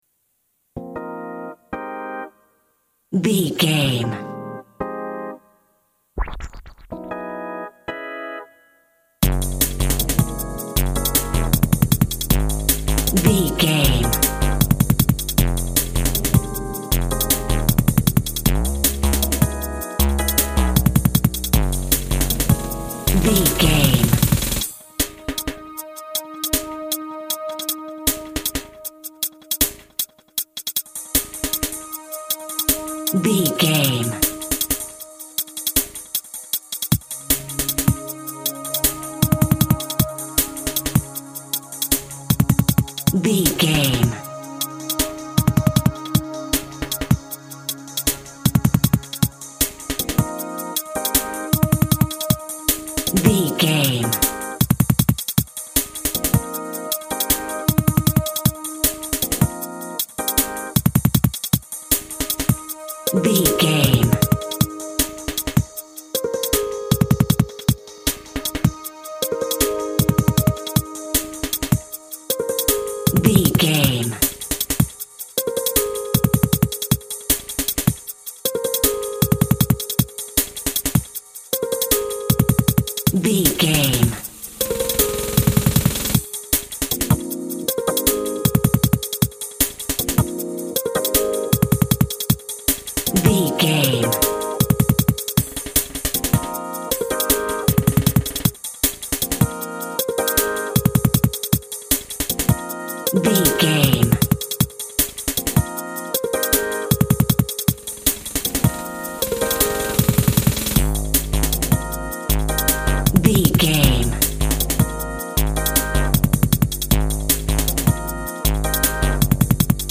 Garage Meets Techno.
Ionian/Major
E♭
Fast
futuristic
hypnotic
driving
energetic
frantic
bouncy
uplifting
drum machine
synthesiser
electric piano
house
trance
synth lead
synth bass